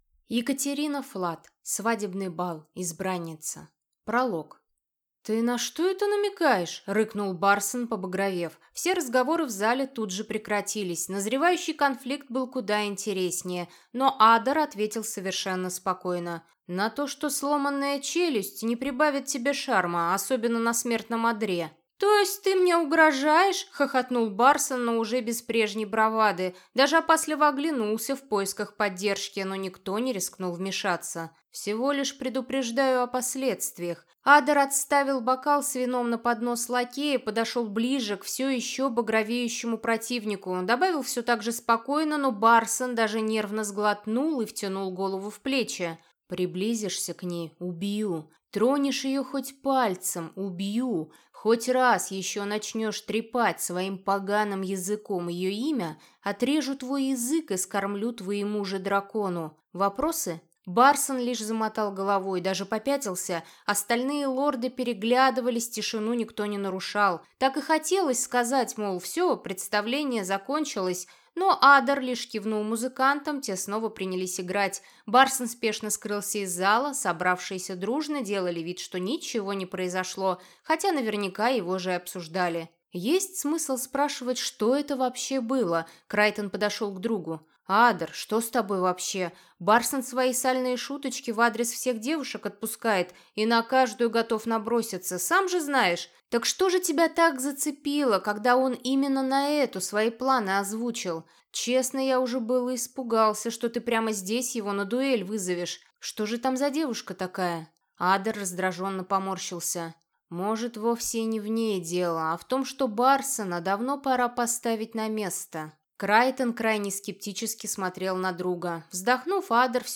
Аудиокнига Свадебный бал. Избранница | Библиотека аудиокниг